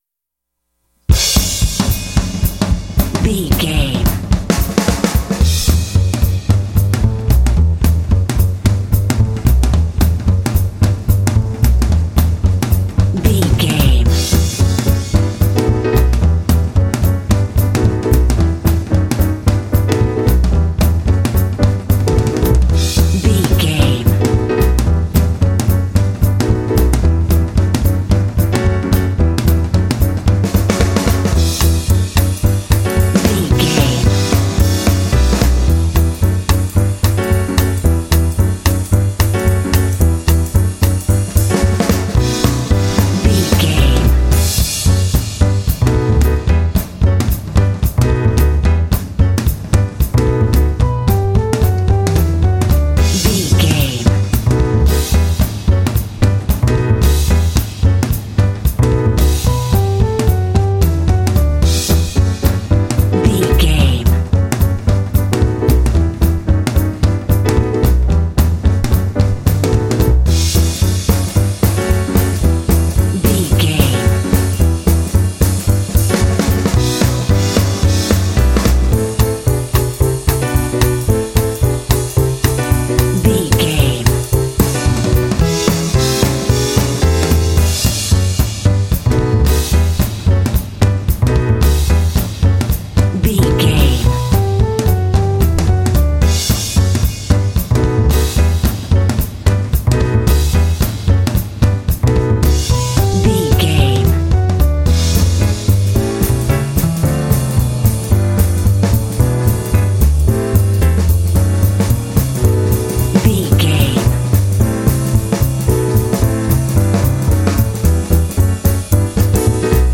Uplifting
Aeolian/Minor
energetic
lively
cheerful/happy
drums
piano
double bass
big band
jazz